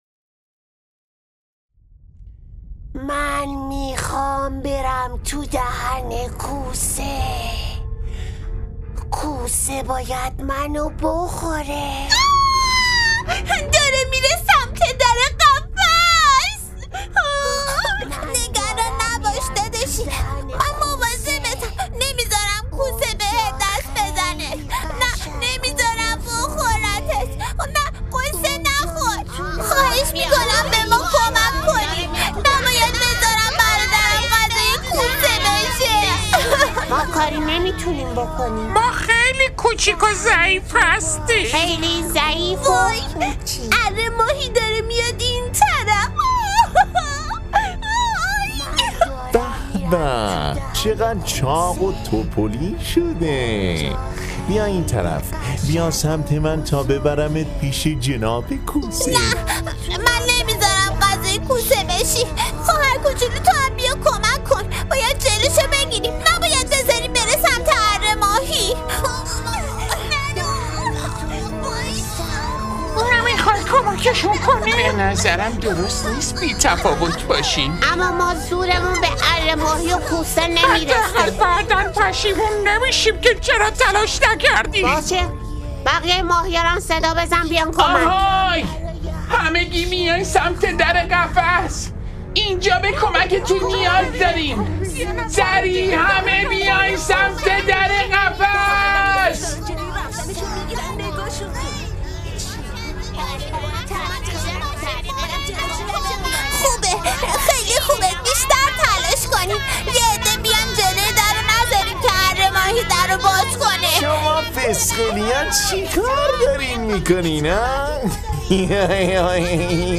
نمایشنامه صوتی در جستجوی نور- قسمت پنجم - متفرقه با ترافیک رایگان
قسمت پنجمدر این نمایشنامه صوتی در جست و جوی نور، مجموعه ای کودکانه به مناسبت عید غدیر خم را خواهید شنید .